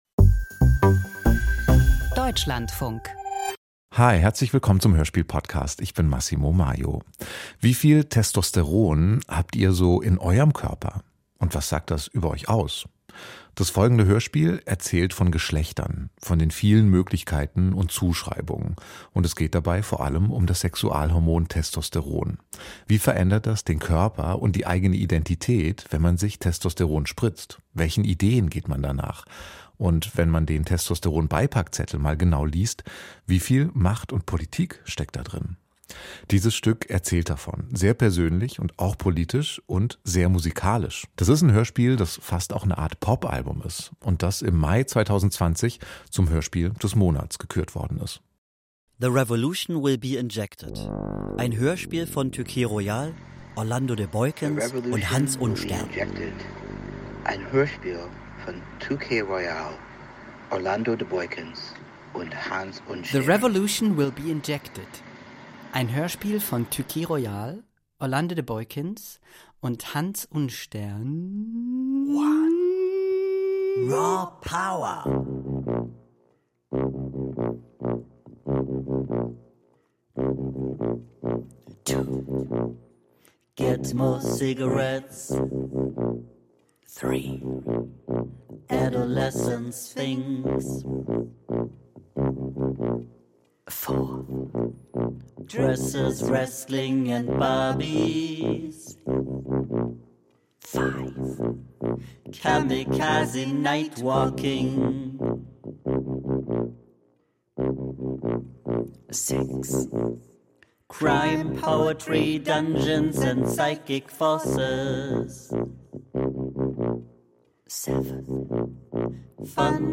• Pop-Hörspiel •